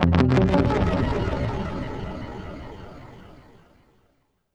GUITARFX18-R.wav